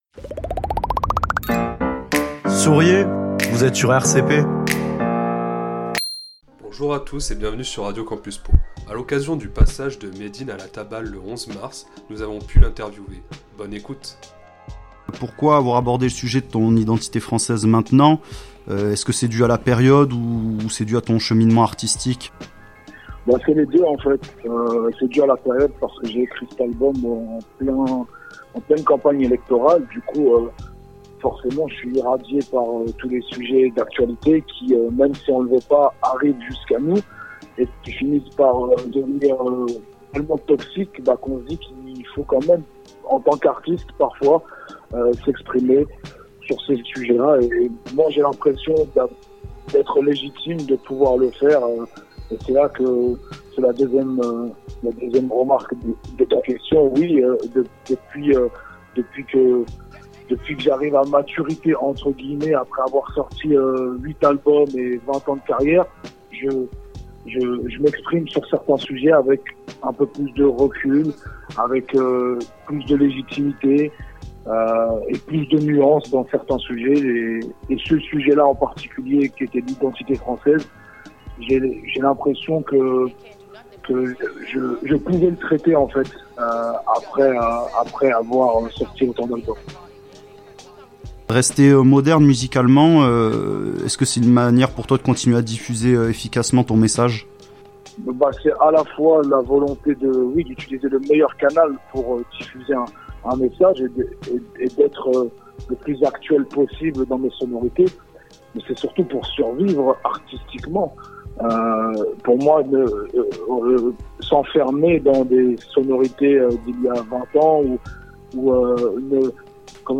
Interview de Médine